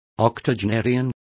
Complete with pronunciation of the translation of octogenarians.